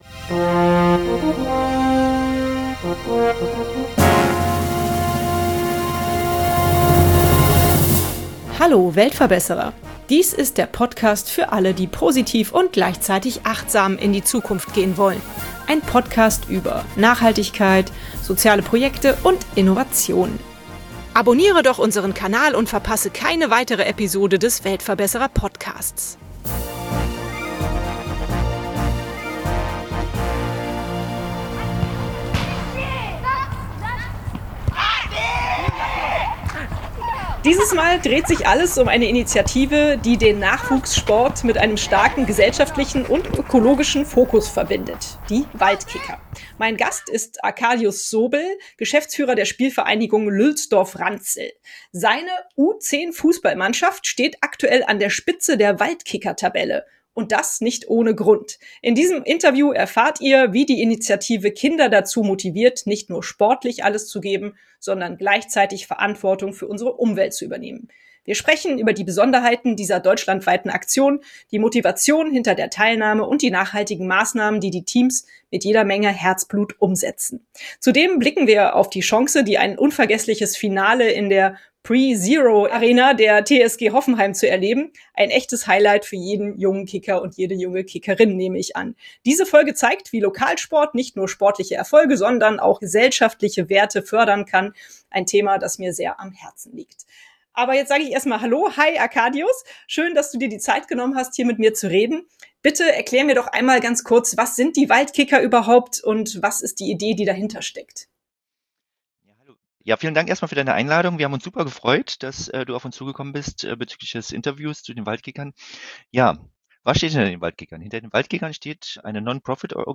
In diesem Interview erfahrt ihr, wie die Initiative Kinder dazu motiviert, nicht nur sportlich alles zu geben, sondern gleichzeitig Verantwortung für unsere Umwelt zu übernehmen. Wir sprechen über die Besonderheiten dieser deutschlandweiten Aktion, die Motivation hinter der Teilnahme und die nachhaltigen Maßnahmen, die die Teams mit jeder Menge Herzblut umsetzen.